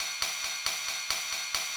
K-5 Ride.wav